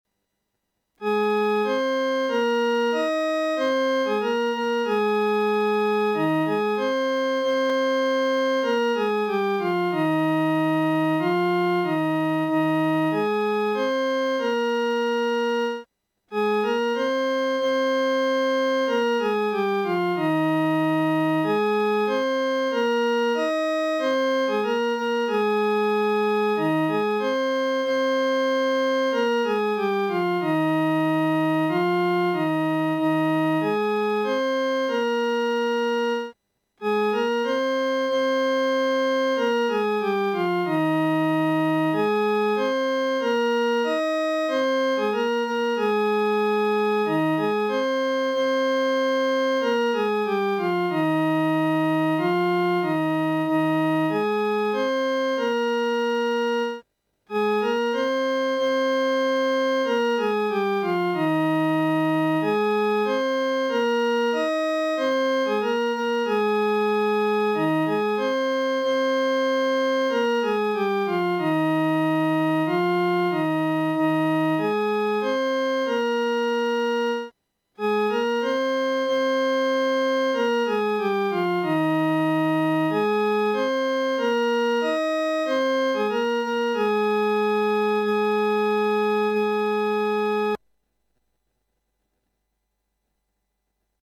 伴奏
四声 下载
诗班在二次创作这首诗歌时，要清楚这首诗歌音乐表情是平静、柔和地。